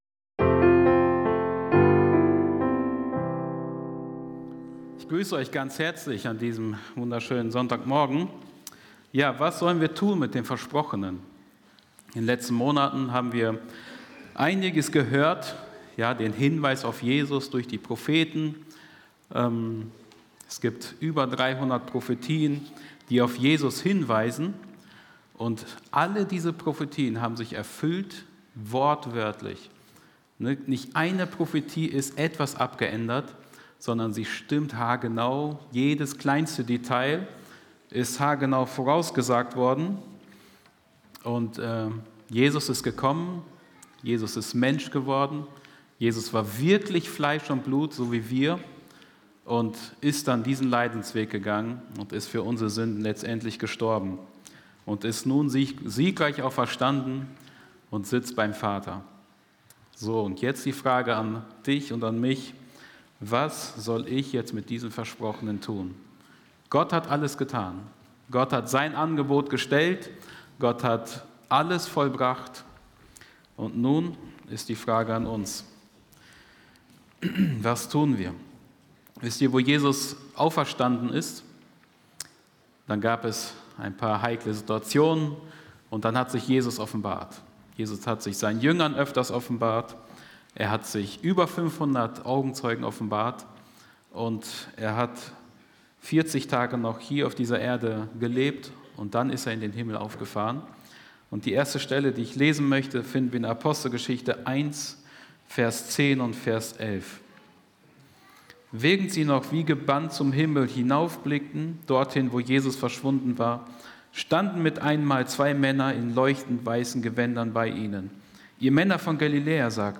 Weiter in der Predigt Reihe - Der Versprochene